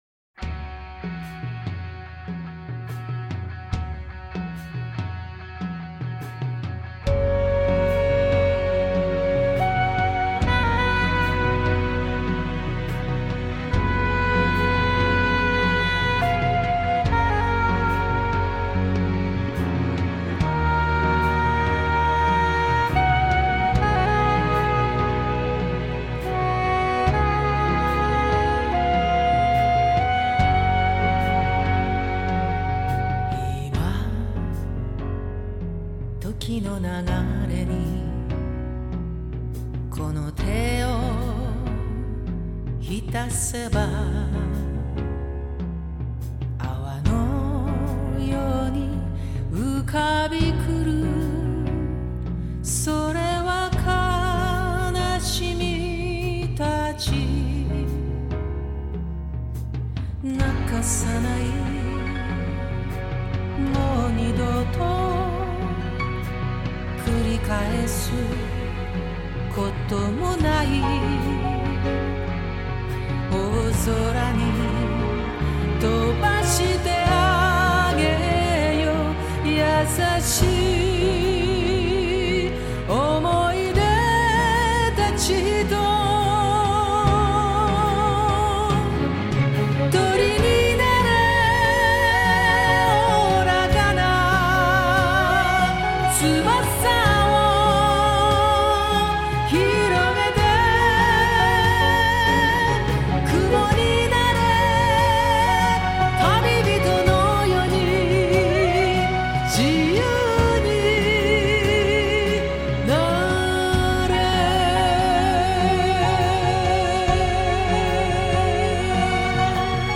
日本版SACD